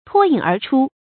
注音：ㄊㄨㄛ ㄧㄥˇ ㄦˊ ㄔㄨ
脫穎而出的讀法